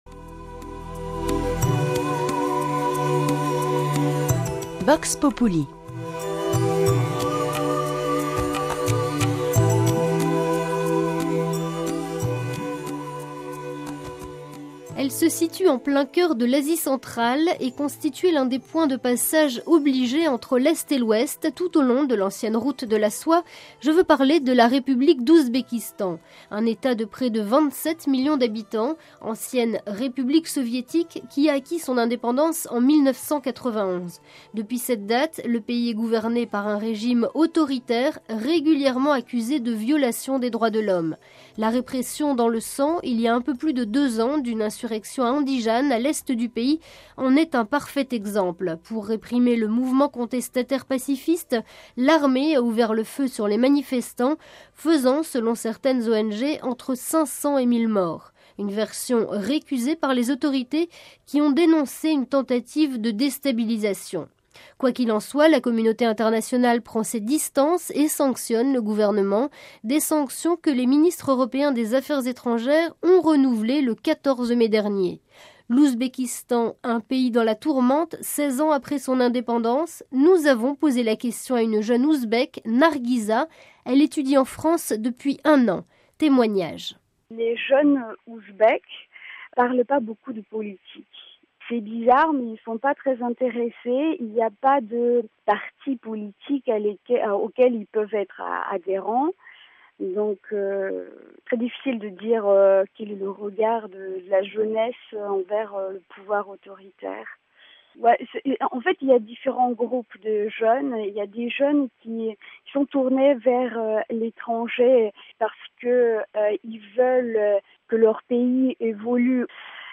Le Témoignage d'une jeune ouzbèke étudiante en France